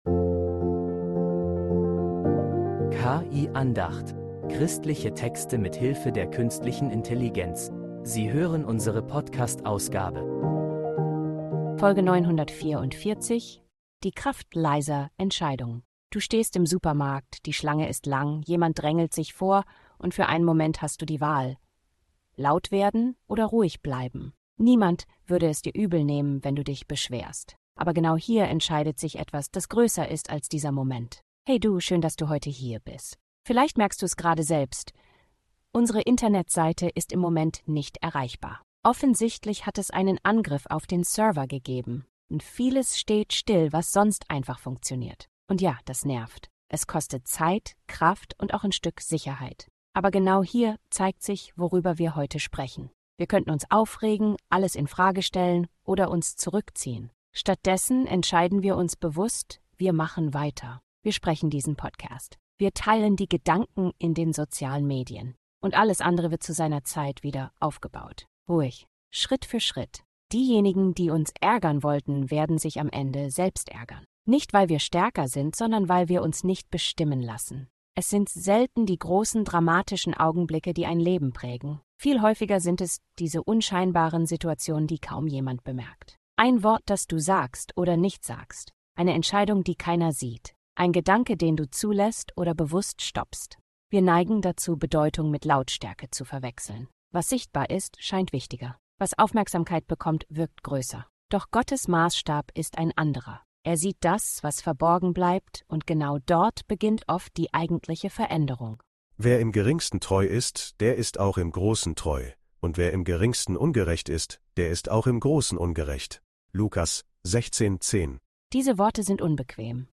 Diese Andacht zeigt dir, warum genau darin deine Stärke liegt.